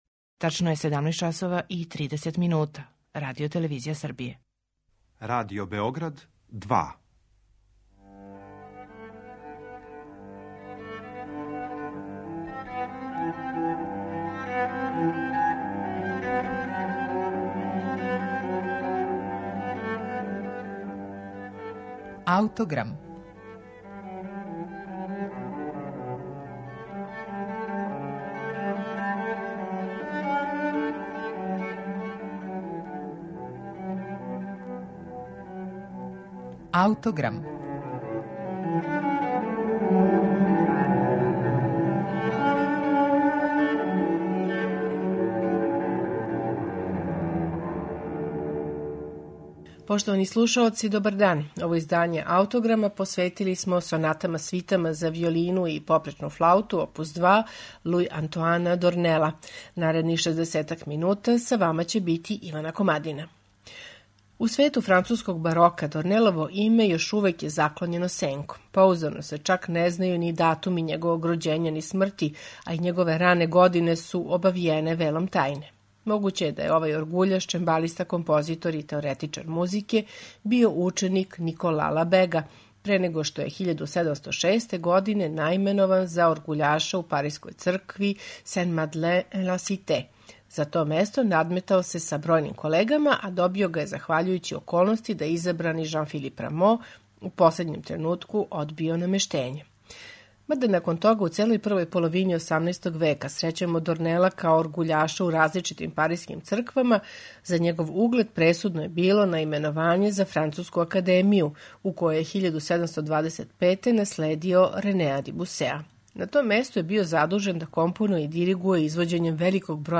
попречне флауте
виола да гамба
чембало), на инструментима који су копије оригиналних инструмената Дорнеловог доба.